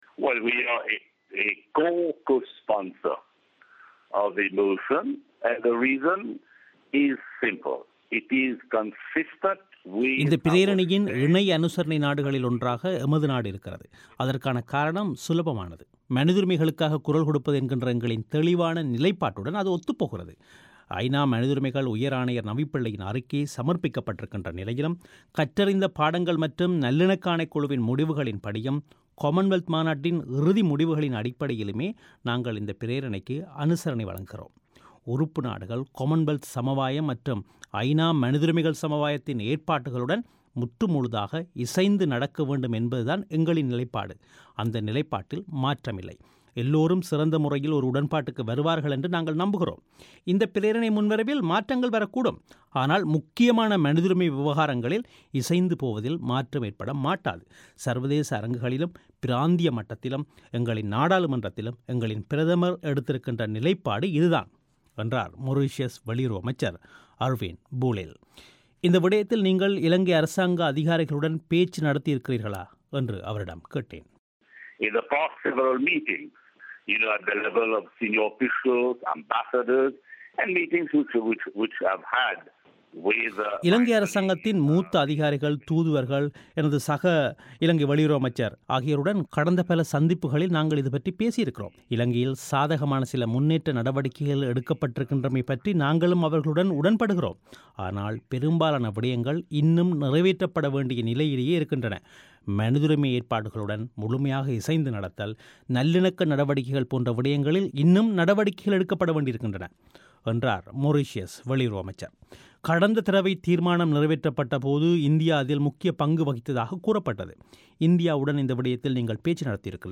இலங்கை மீதான தீர்மானத்திற்கு இணை- அனுசரணை அளிப்பதற்கு காரணம் என்ன என்று மொரீஷியஸ் வெளியுறவு அமைச்சரிடம் தமிழோசை வினவியது